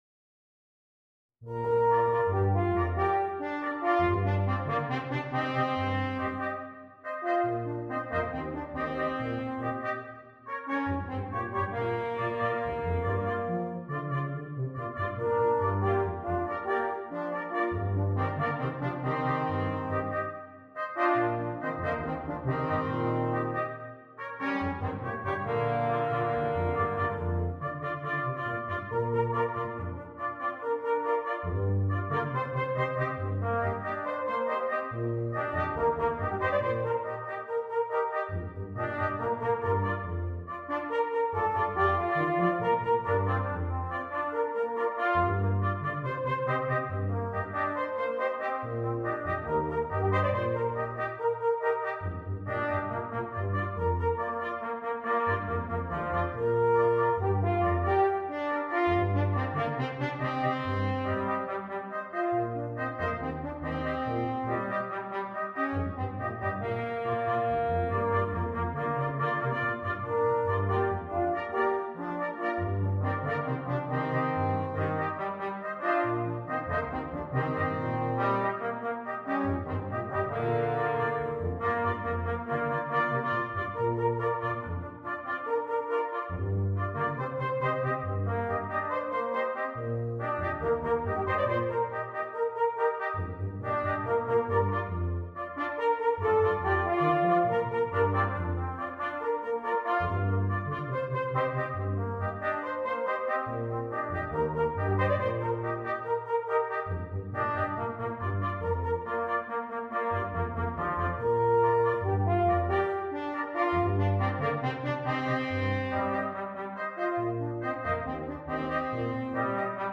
для брасс-квинтета.